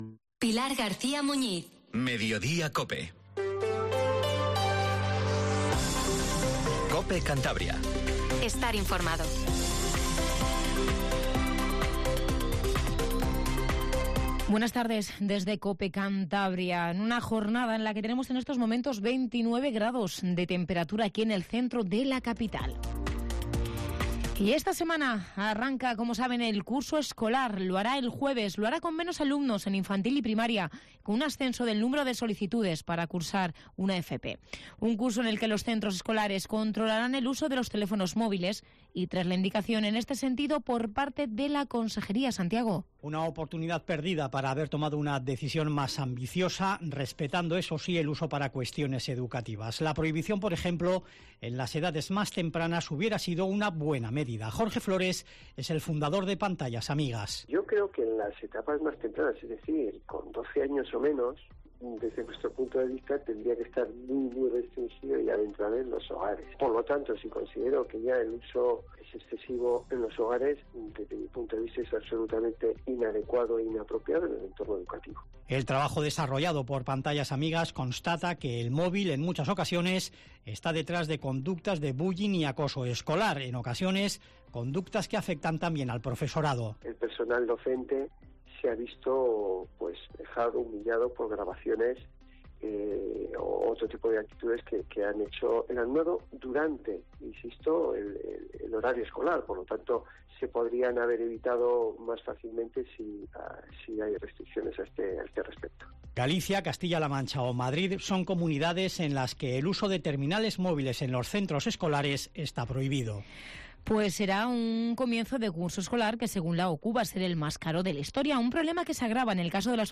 Informativo Regional 14:50